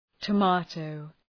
Προφορά
{tə’meıtəʋ}